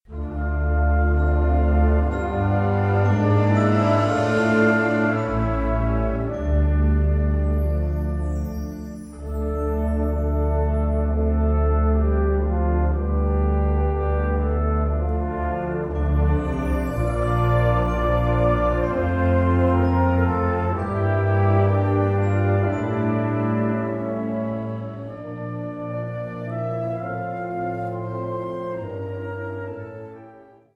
Categoria Concert/wind/brass band
Sottocategoria Musica per concerti
Andante 2:53